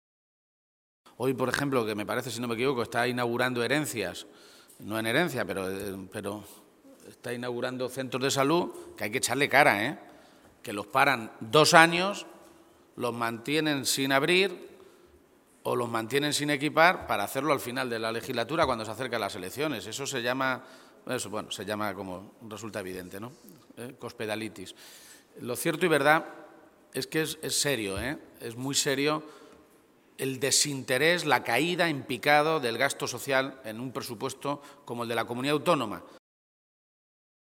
García-Page se pronunciaba de esta manera esta mañana, en Toledo, a preguntas de los medios de comunicación en una comparecencia en la que insistía en denunciar el desmantelamiento de la sanidad pública “y la caída brutal del gasto social en los Presupuestos de Castilla-La Mancha desde que Cospedal es Presidenta”.
Cortes de audio de la rueda de prensa